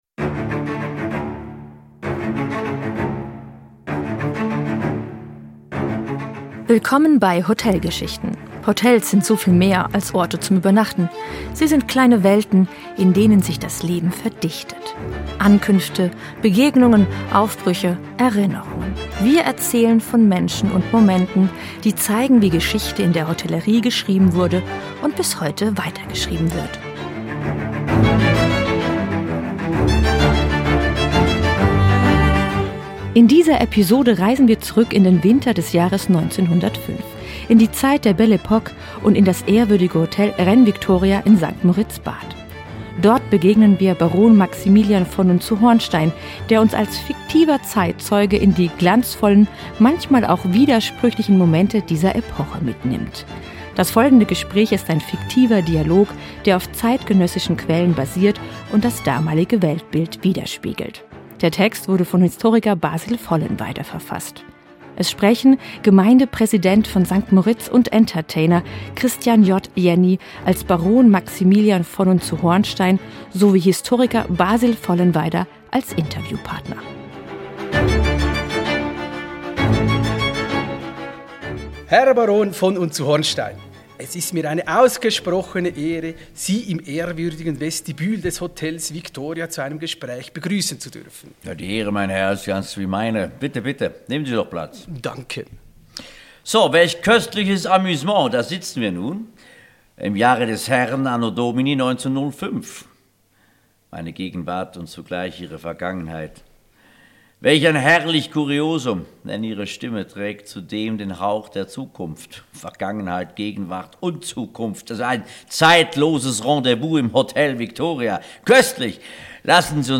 In dieser Episode reisen wir zurück in den Winter des Jahres 1905 – ins ehrwürdige Hotel Victoria in St. Moritz-Bad. Im Zentrum steht Baron Maximilian von und zu Hornstein, eine fiktive Figur, die als erzählerische Stimme durch diese Zeit führt.